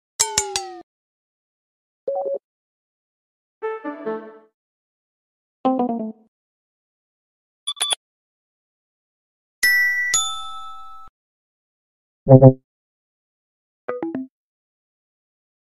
Low Battery Sounds Part 2 sound effects free download